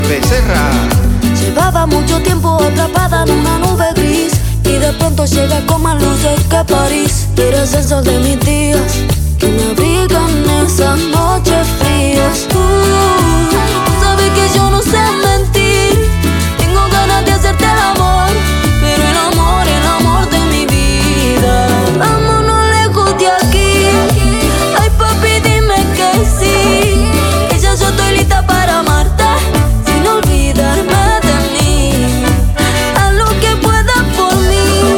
# Música Mexicana